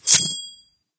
bp_snout_coin_02.ogg